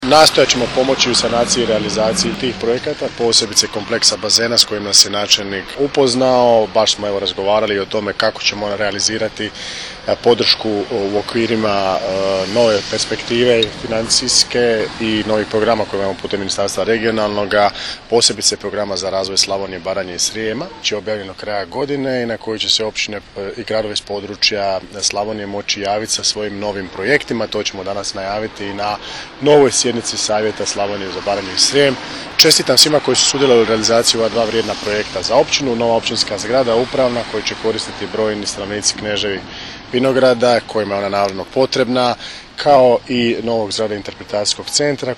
Ministar Erlić je tijekom svog obraćanja pohvalio trud lokalne uprave u realizaciji ovih projekata. Naglasio je da Ministarstvo regionalnog razvoja i fondova EU nastavlja s intenzivnim radom na novim programima usmjerenima na razvoj Slavonije, Baranje i Srijema.